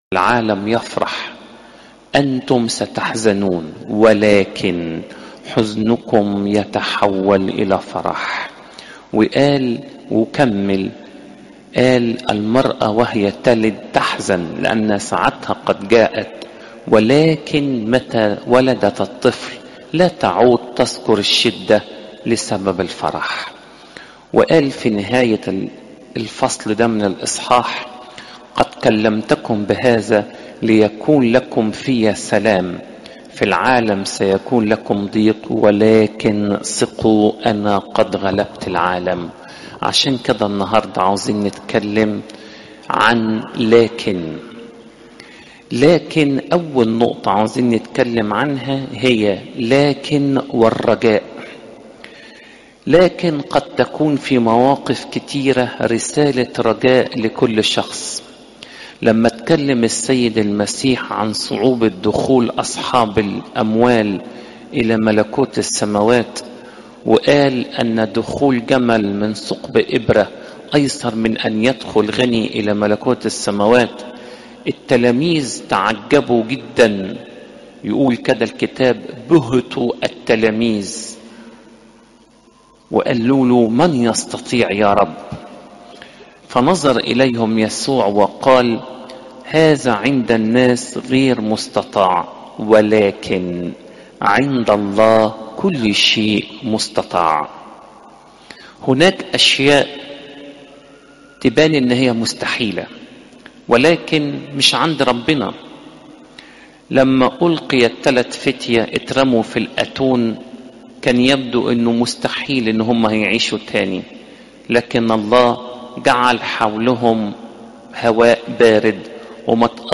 عظات قداسات الكنيسة صوم الميلاد (يو 16 : 20 - 33)